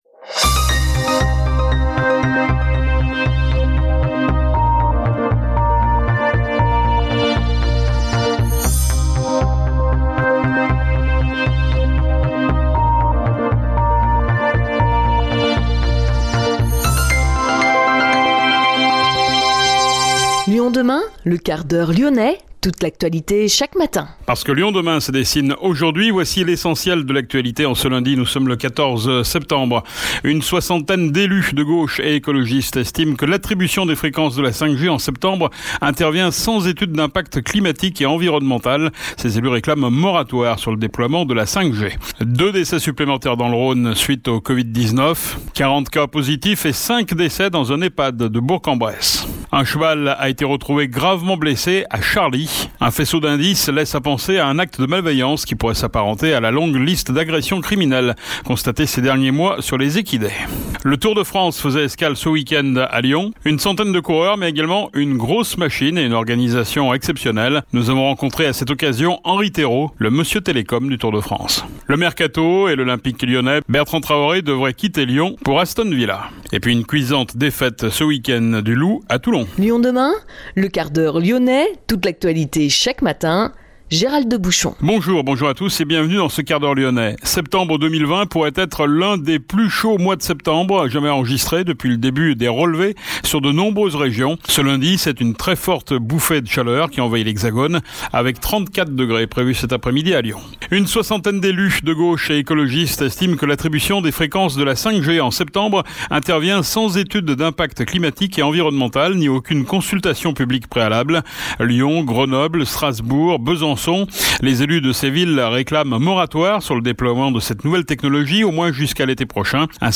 Le Quart-d’Heure Lyonnais est aussi diffusé en FM sur Pluriel 91,5, Salam 91,1, Judaïca 94,5 et Arménie 102,6